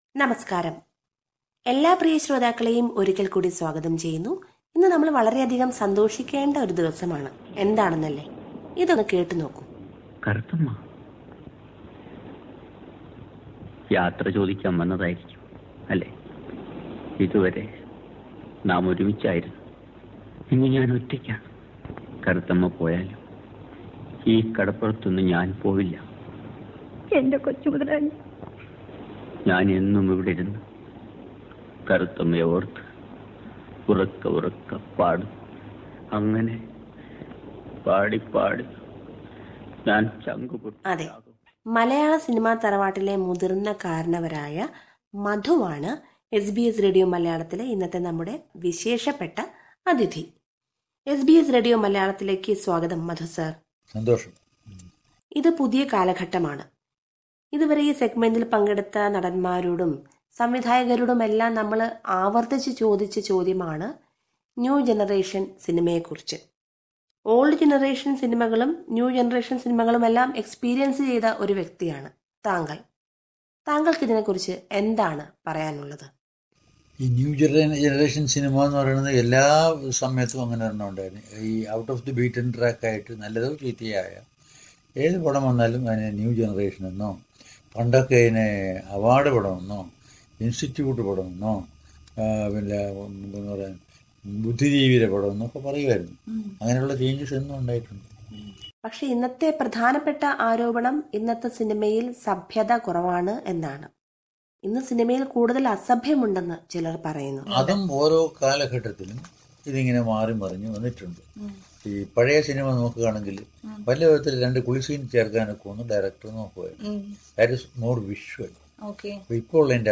SBS Malayalam